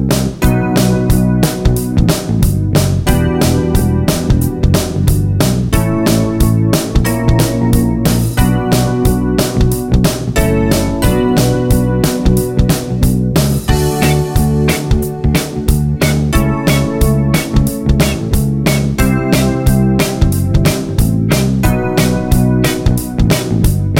No Sax Pop (1980s) 4:23 Buy £1.50